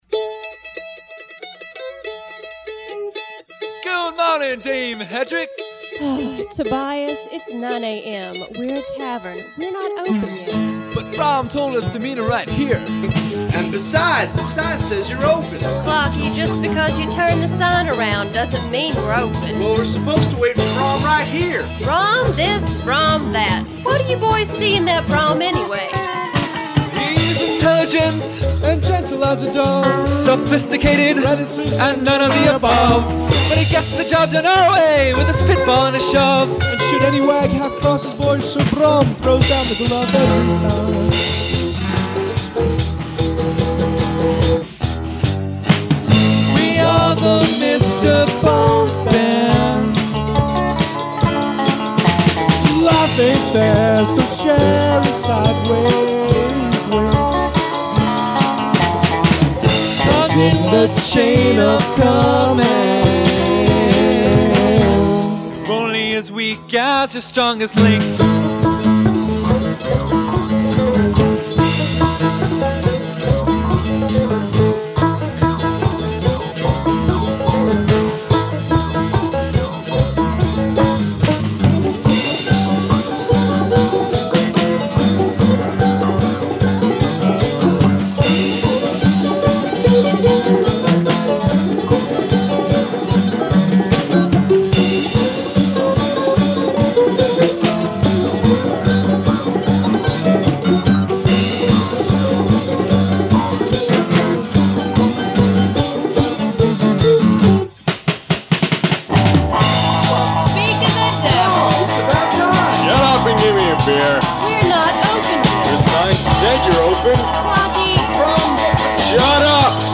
drums, banjo, mouth harp, vocals